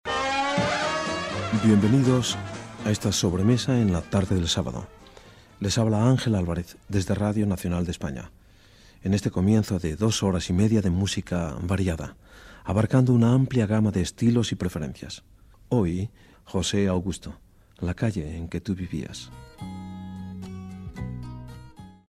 Presentació del programa i tema musical
Musical